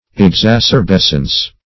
([e^]gz*[a^]s`[~e]r*b[e^]s"sens)